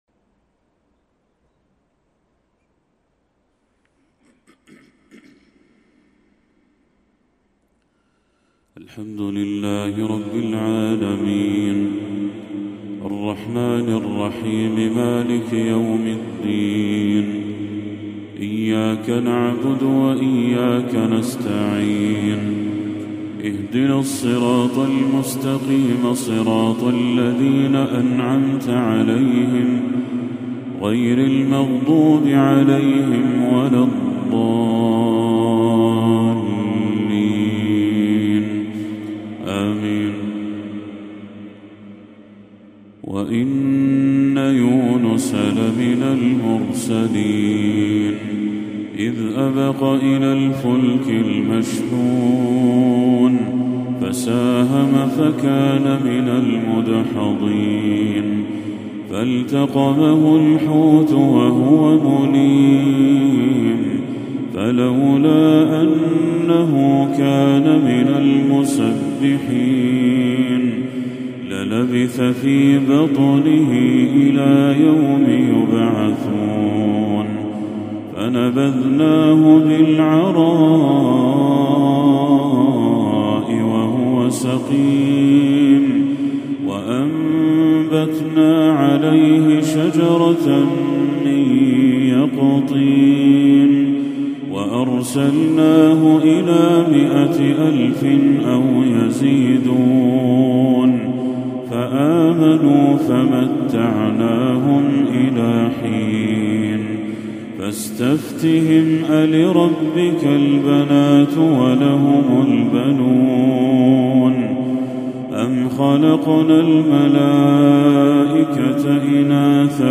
تلاوة لخواتيم سورة الصافات للشيخ بدر التركي | عشاء 15 ربيع الأول 1446هـ > 1446هـ > تلاوات الشيخ بدر التركي > المزيد - تلاوات الحرمين